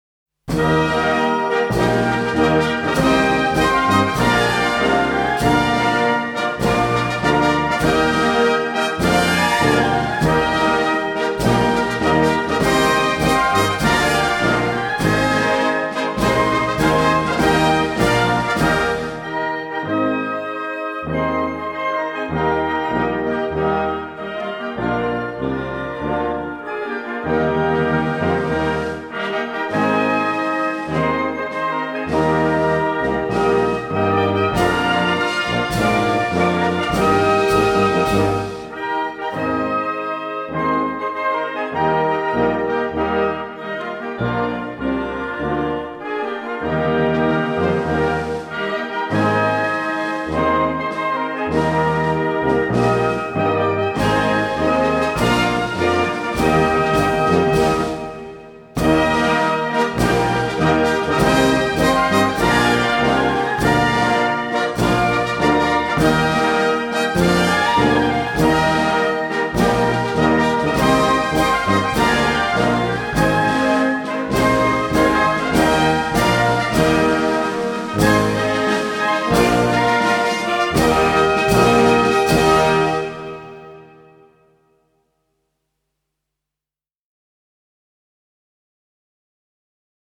Aus diesem Anlass lud die Steiermärkische Landesregierung die Angehörigen des Militärkommandos zu einem in der Öffentlichkeit viel beachteten gemeinsamen Festakt in den Hof und den Repräsentationsräume der Grazer Burg.
steiermark_fanfare.mp3